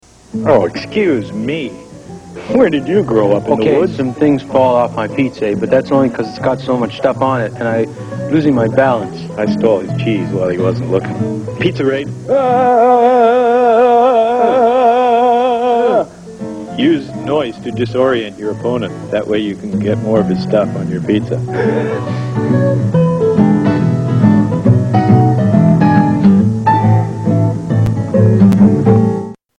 Pizza Hut Commercial 1985
Category: Comedians   Right: Personal